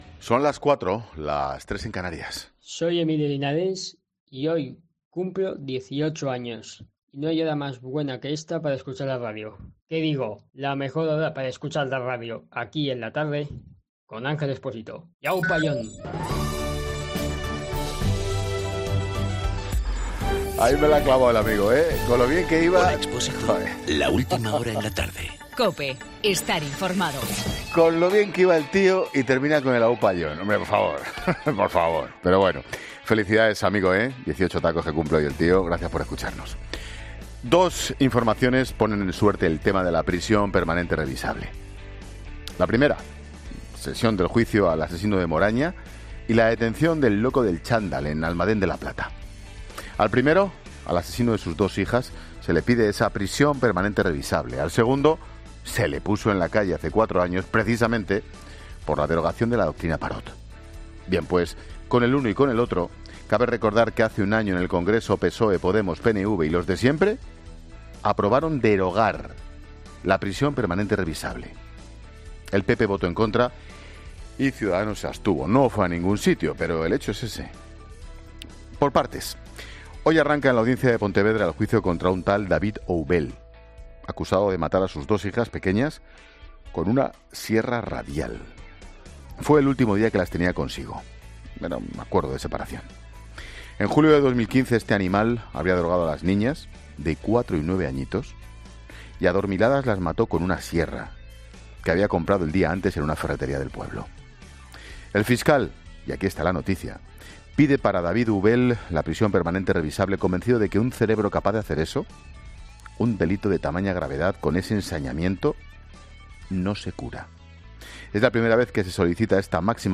Monólogo de Ángel Expósito de las 16h sobre la prisión permanente revisable.